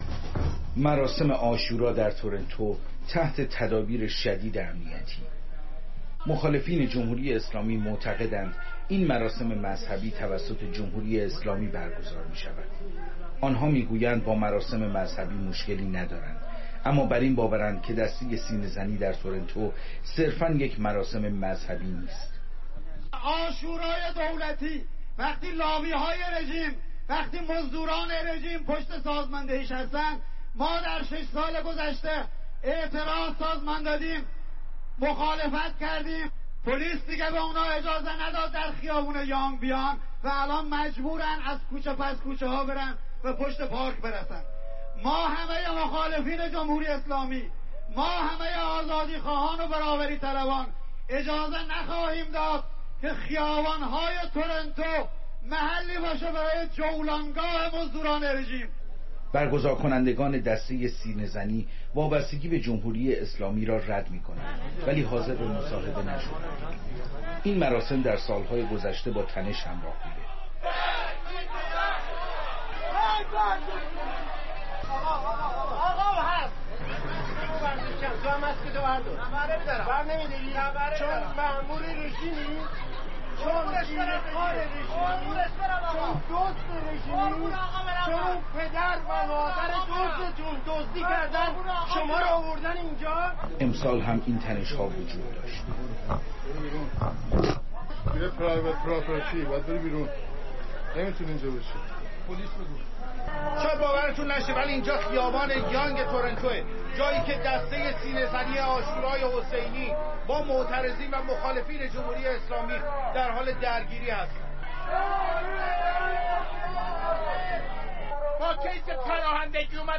از تورنتو گزارش می‌دهد